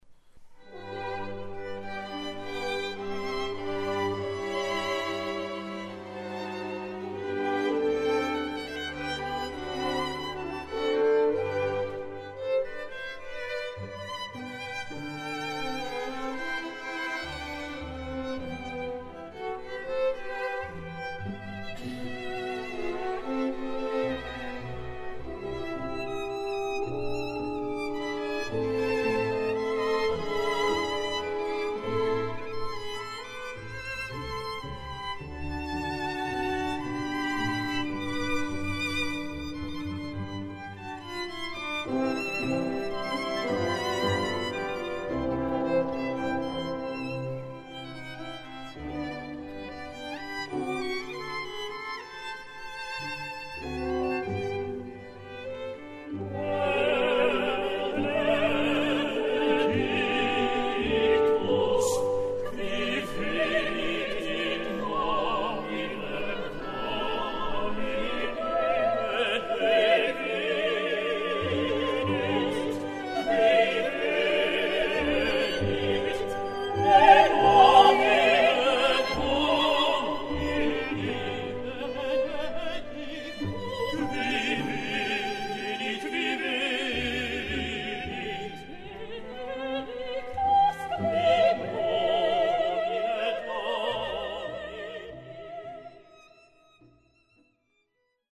für gemischten Chor, vier Soli und Orchester
Überregionaler Chor Musica Sacra Lilienfeld, Kilb und Wilhelmsburg
Sinfonieorchester TonkünstlerEnsemble Wien/Lilienfeld